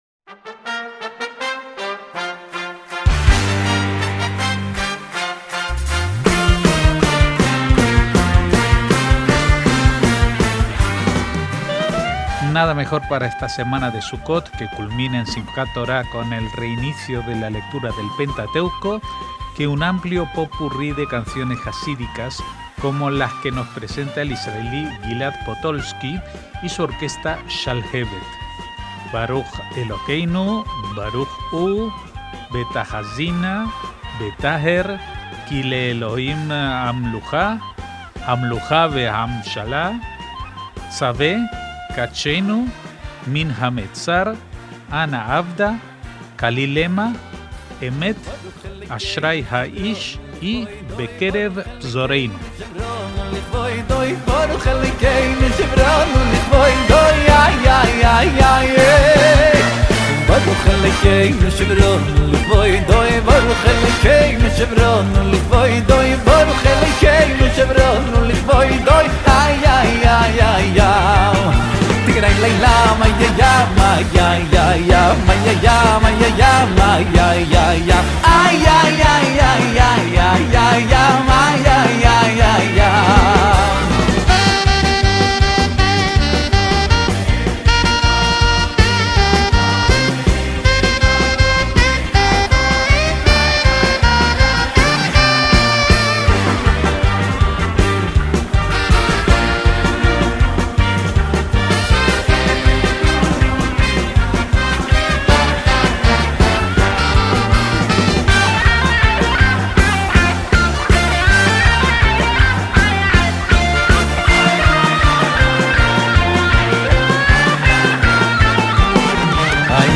MÚSICA ISRAELÍ
un amplio popurrí de canciones jasídicas